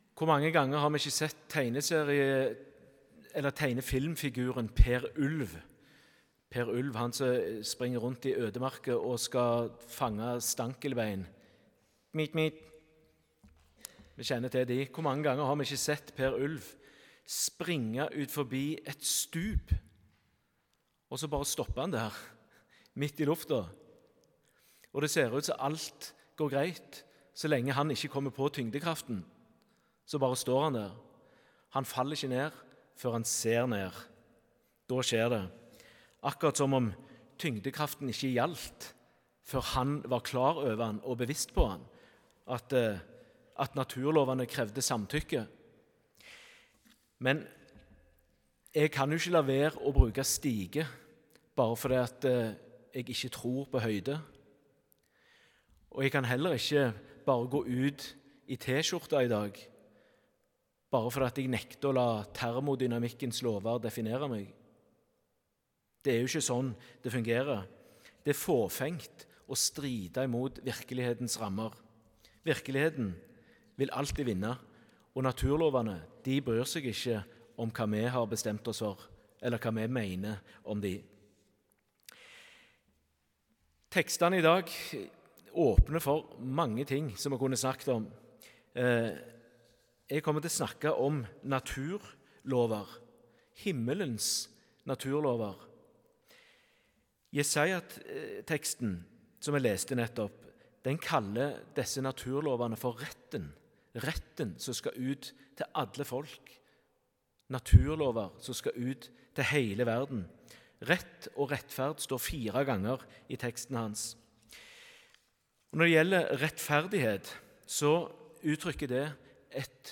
Tekstene Evangelietekst: Matt 3,13–17 Lesetekst 1: Jesaja 42,1–6 Lesetekst 2: Apg 18,24–19,7 Utdrag fra talen (Hør hele talen HER ) Guds naturlov Guds lov = ‘naturlover’ for himlenes rike – akkurat slik naturen rundt oss har sine lover.